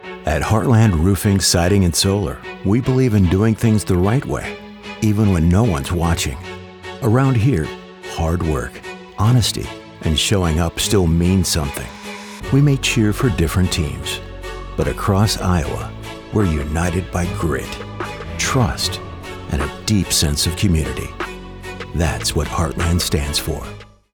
Male
Adult (30-50), Older Sound (50+)
My voice is warm, textured, and engaging, conveying a wide range of emotions.
Radio Commercials